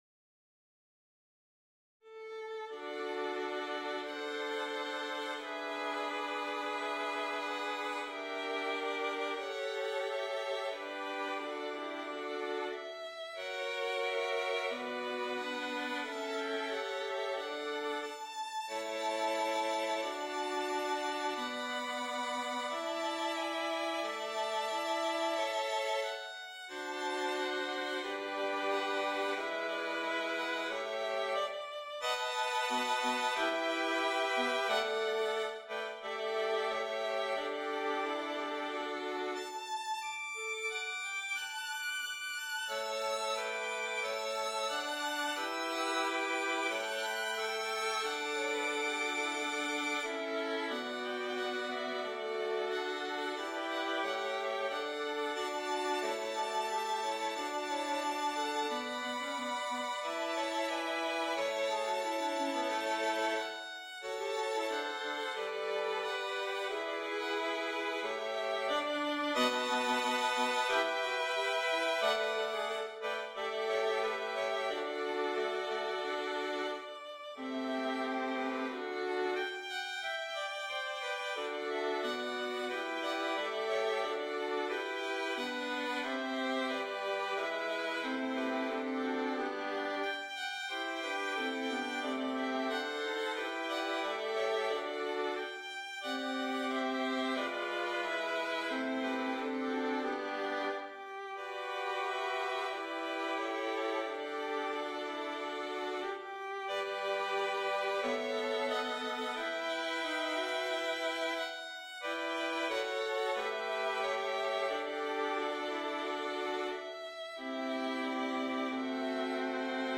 A lovely, intermediate rendition of two famous hymns
Piano part included.
Hymns for Four Violins MP3 Sample (entire) »